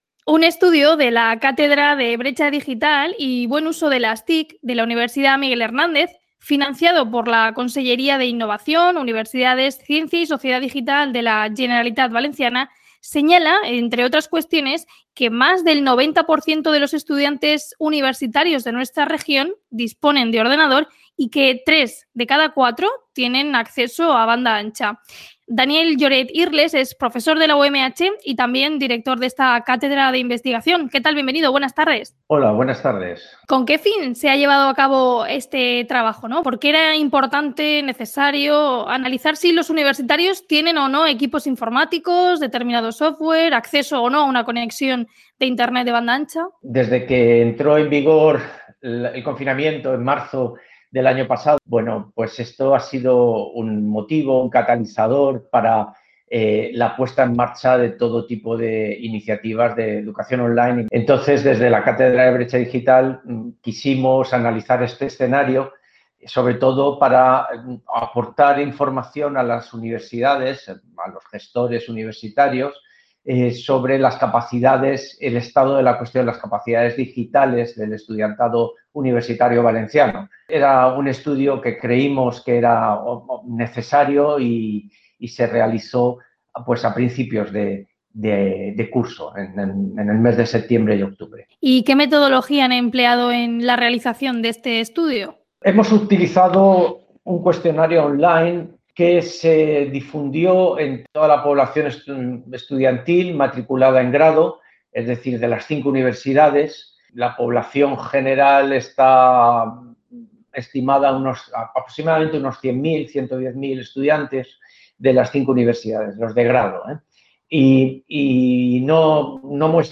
Este programa de noticias se emite de lunes a viernes, de 14.00 a 14.10 h